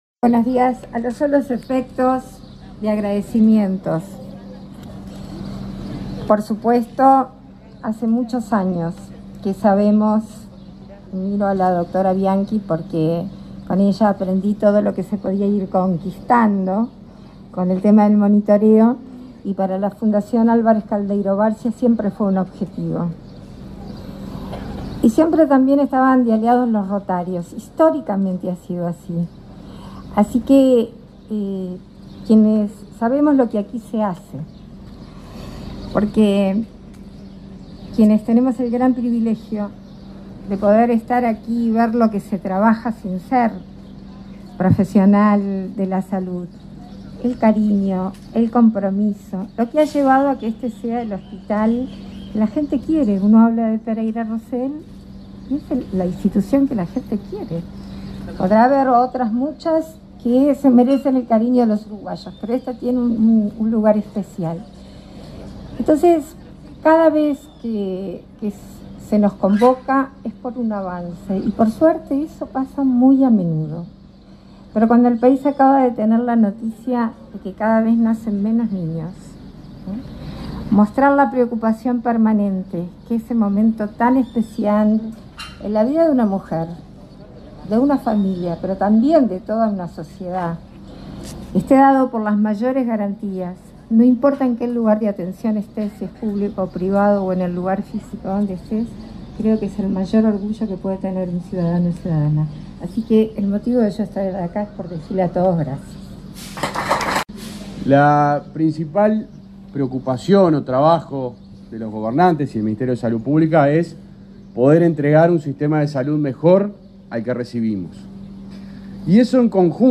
Palabras de autoridades en acto en el hospital Pereira Rossell